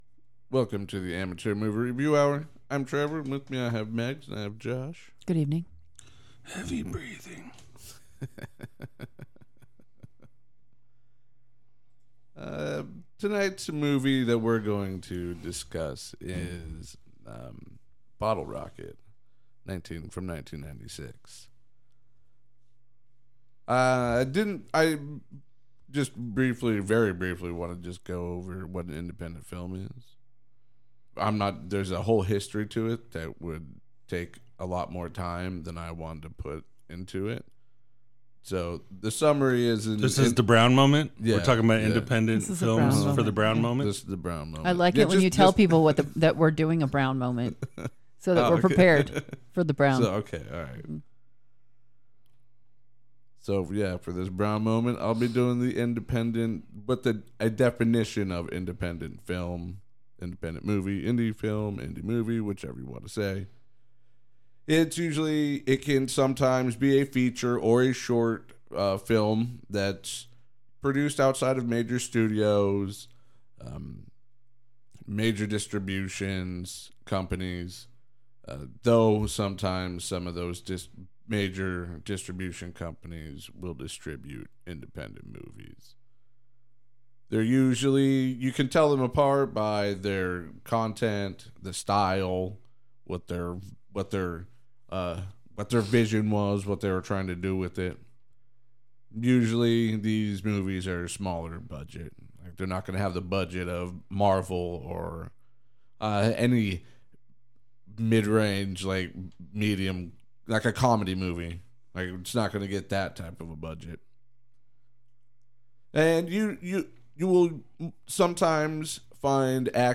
These 3 amateur reviewers will amuse you with their one of a kind movie opinions, jolly critiques, and their tales of personal experiences with these movies.